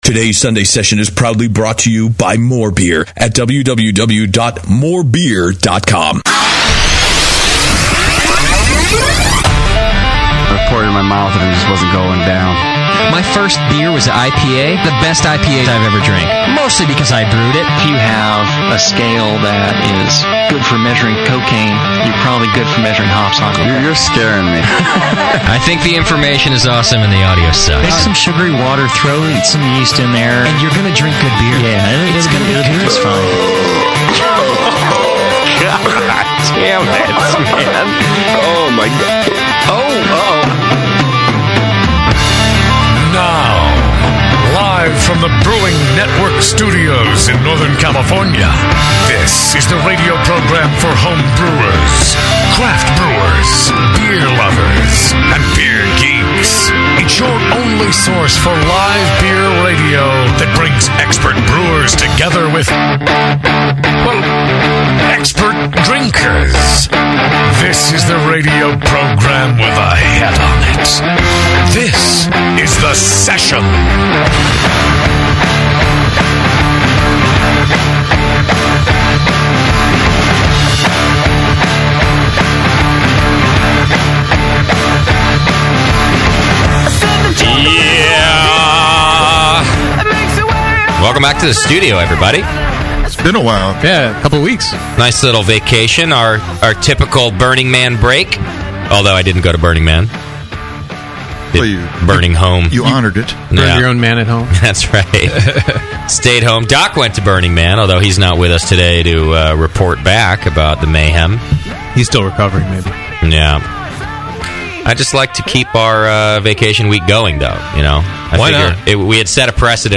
Ég rakst á viðtal á hinni frábæru Brewing Network síðu